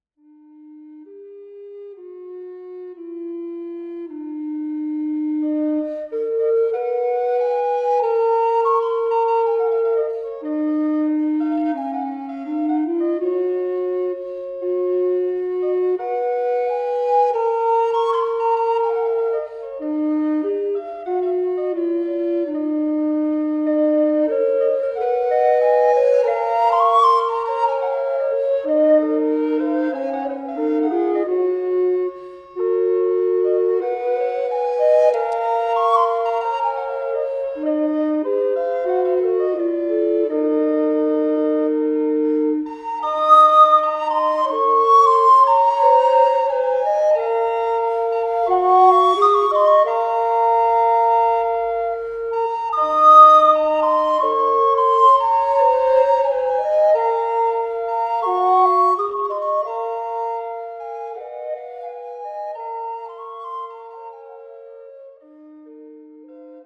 TriTonus Hamburg das Blockflötenensemble - Audio - Hörbeispiele
Aus dem Repertoire: Mittelalter